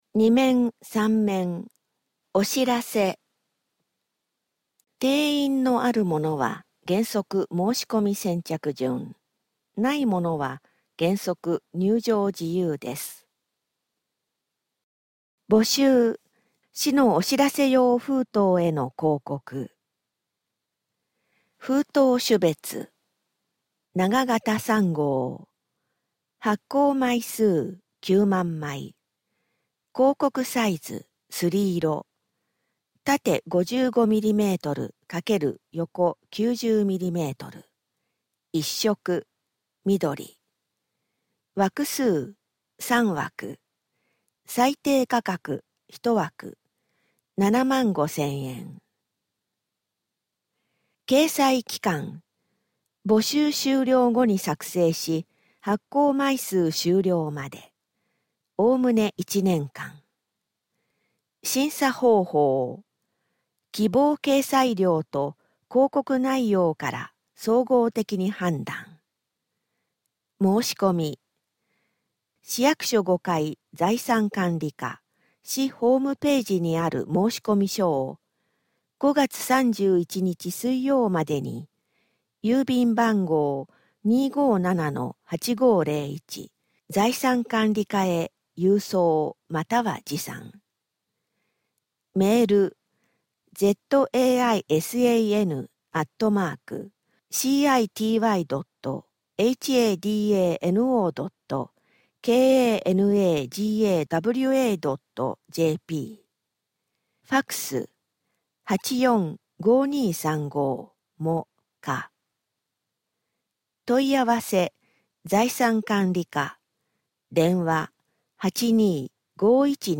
主な記事 1面「ニュースポーツ」 2-3面「お知らせ」 4面「小・中学生の夏休み体験イベント」 折込面「はだのチャレンジデー特集号」 PDF版 全面（PDF/5MB） 声の広報 1面（MP3／4MB） 2-3面（MP3／12MB） 4面（MP3／12MB） 折り込み面（MP3／8MB） テキスト版 全面（TXT/45KB） 紙面アンケート 今後の紙面作成の参考とするため、アンケートにご協力をお願いします。